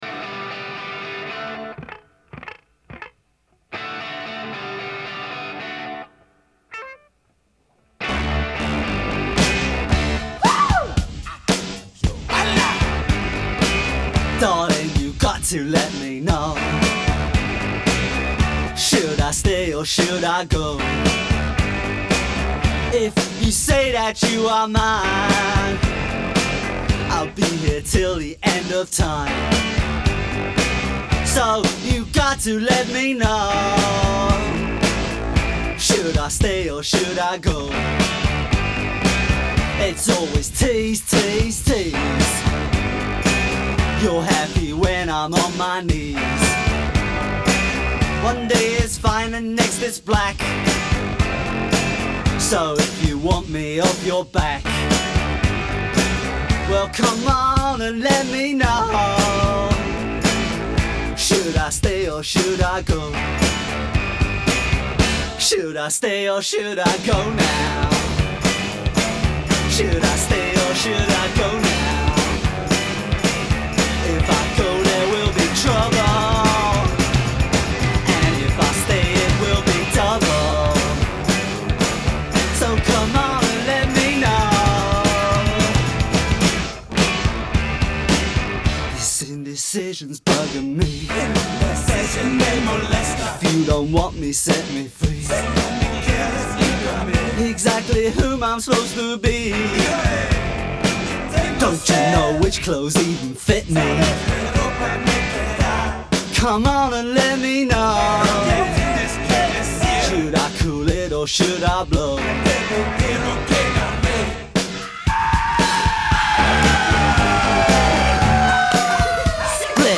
Rock 80er